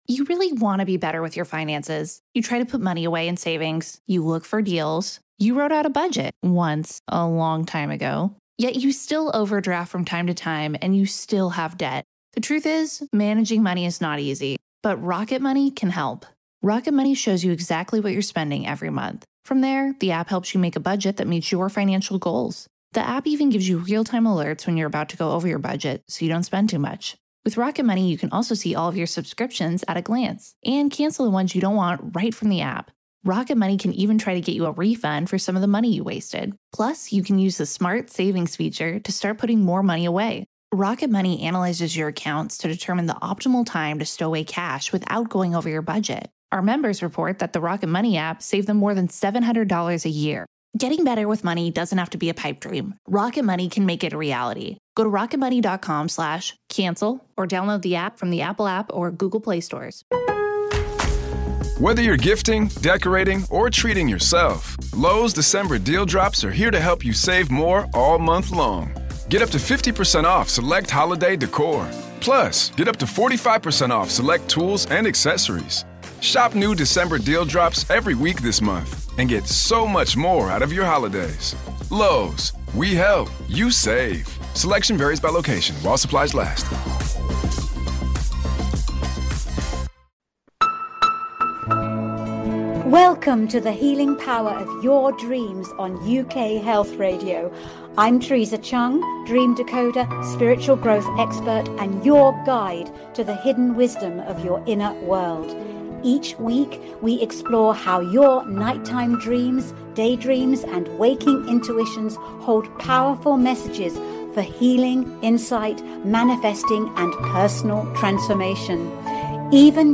The Healing Power of Dreams explores the transformative power of nocturnal dreams and day dreams for your mental, emotional, physical, and spiritual wellbeing. Each live episode dives deep with leading scientists, psychologists, authors, and consciousness researchers, plus the occasional celebrity guest sharing their own dream stories.